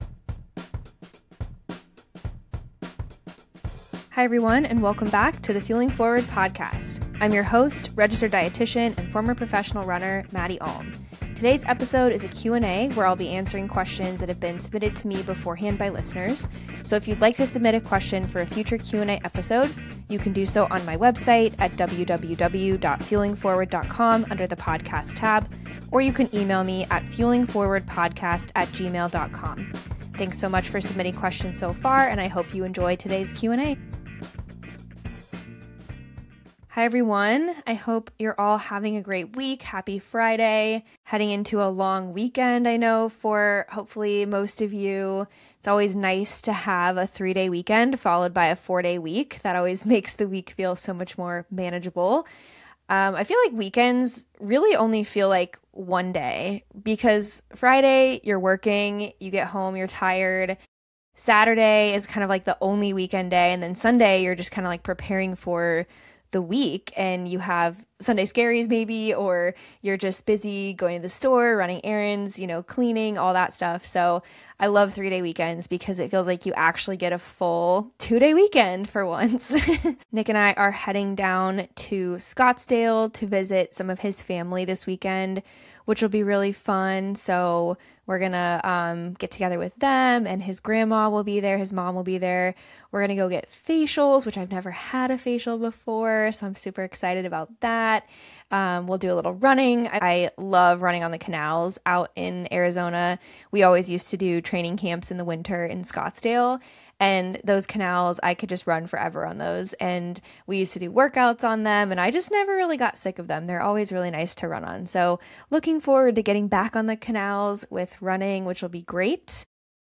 This episode is a Q&A episode where I answer questions that have been submitted by listeners.